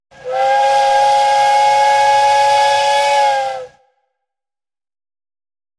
Звук гудка паровоза